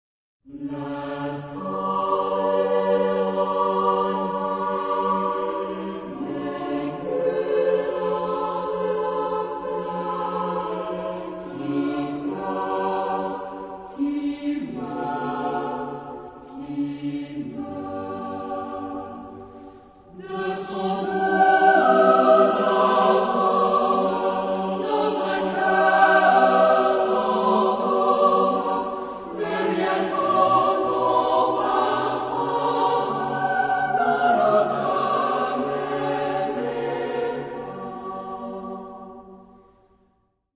Genre-Style-Form: Popular ; Partsong ; Secular
Type of Choir: SATB  (4 mixed voices )
Tonality: G major
Consultable under : 20ème Profane Acappella